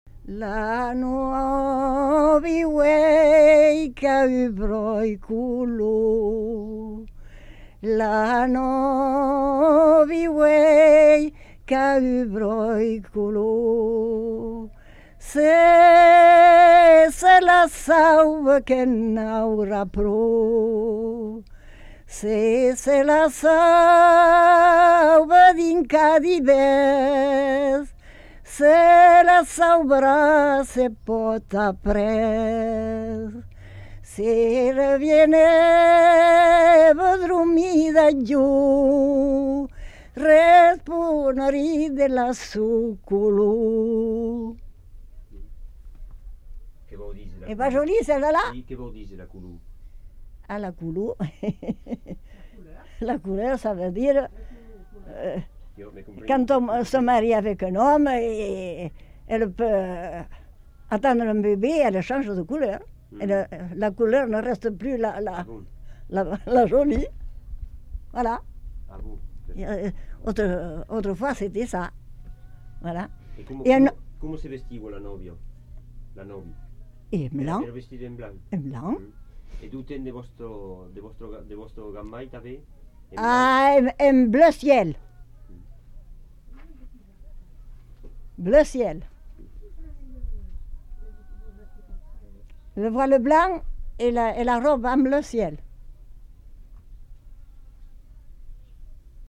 Aire culturelle : Marsan
Lieu : Mont-de-Marsan
Genre : chant
Effectif : 1
Type de voix : voix de femme
Production du son : chanté